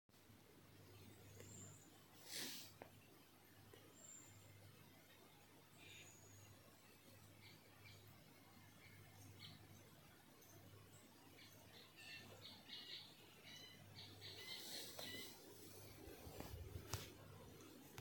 Sound recording: The Eurasian Treecreeper at Xiaolongmen on 2 February 2022